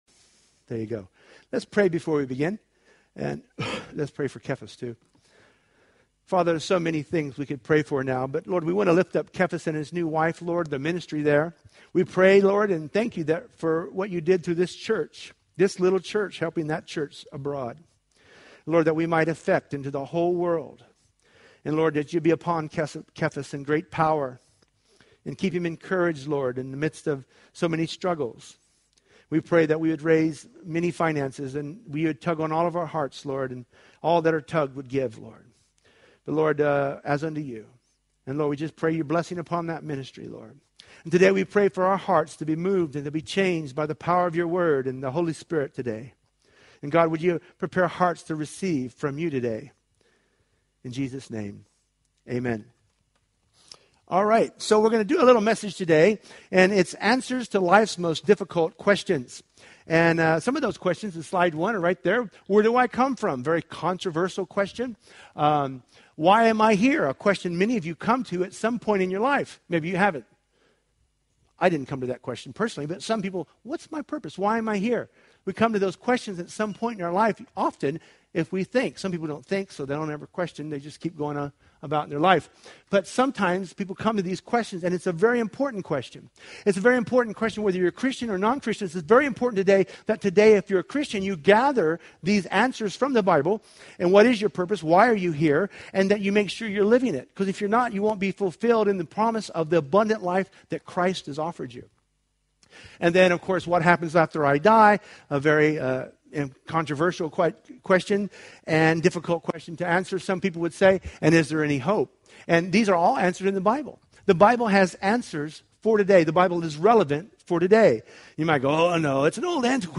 Preached at a Taste & See Evangelistic Event